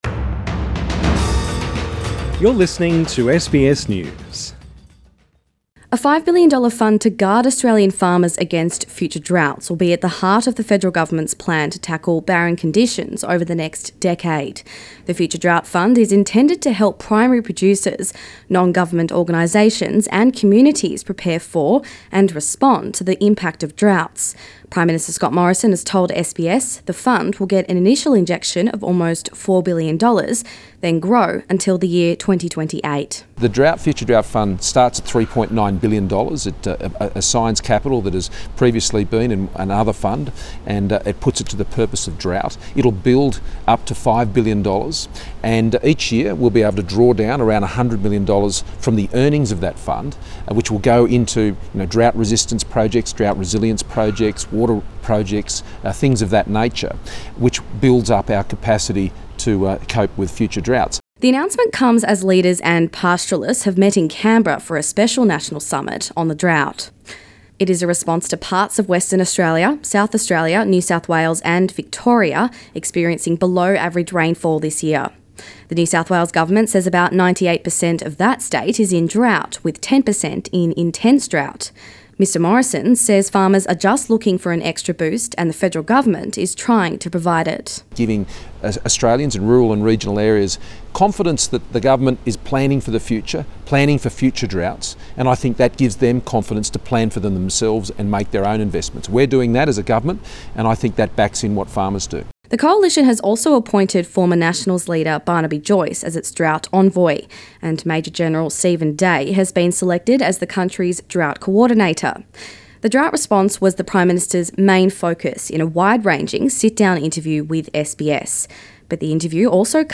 In an exclusive interview with SBS, Mr Morrison has also spoken on Australia's refugee policy, China, Wikileaks founder Julian Assange and the leadership spill that toppled former prime minister Malcolm Turnbull.